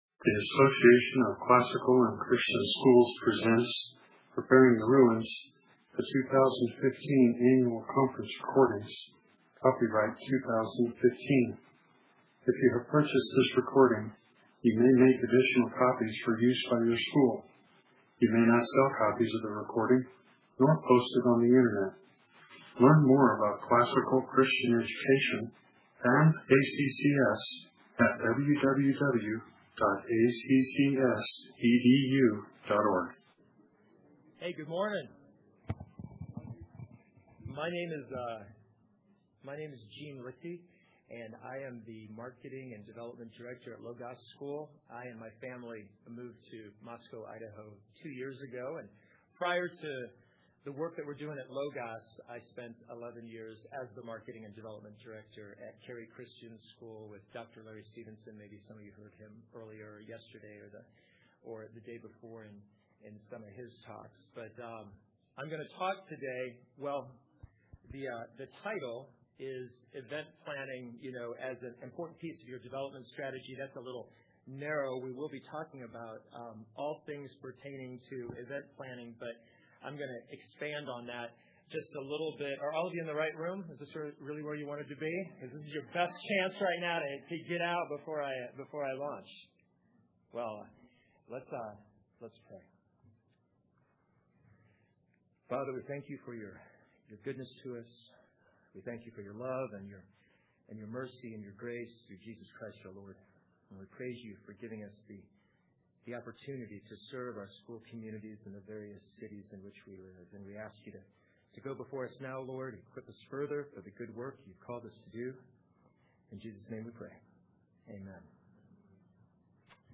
2015 Workshop Talk | | Fundraising & Development, Marketing & Growth
Additional Materials The Association of Classical & Christian Schools presents Repairing the Ruins, the ACCS annual conference, copyright ACCS.